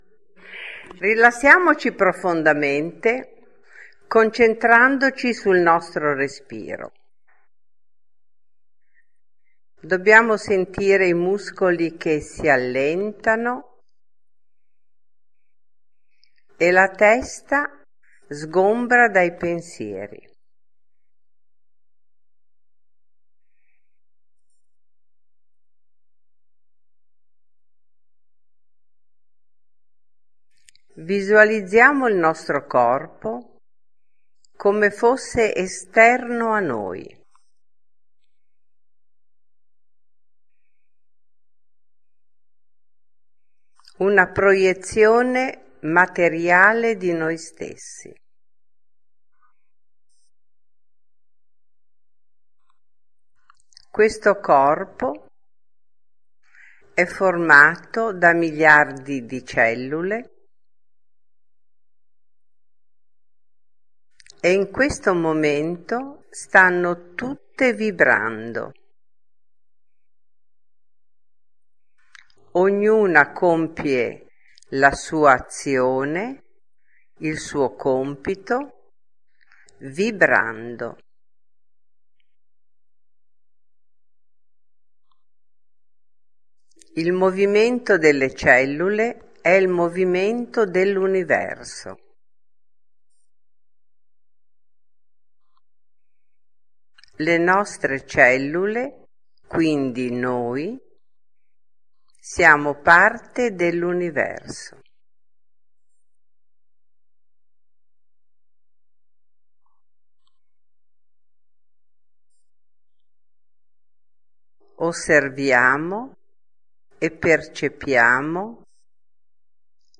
Danza meditazione